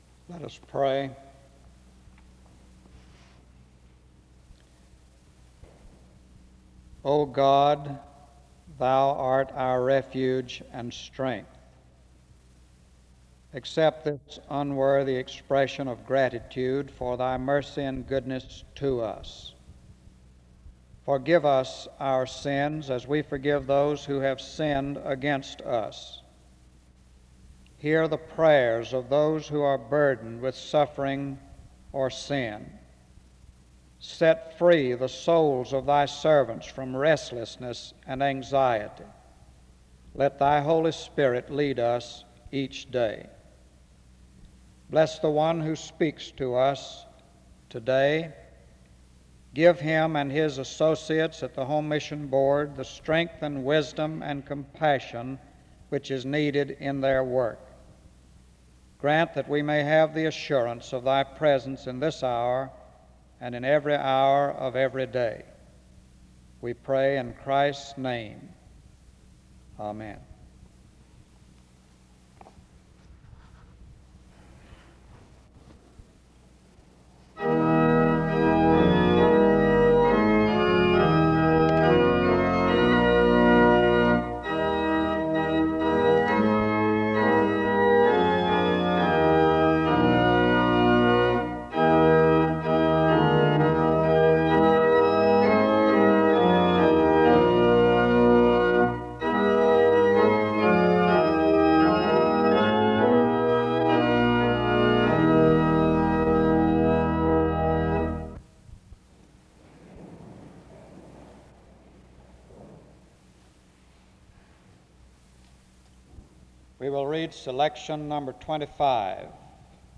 The service begins with a word of prayer from 0:00-1:10. Music plays from 1:16-1:50.
SEBTS Chapel and Special Event Recordings SEBTS Chapel and Special Event Recordings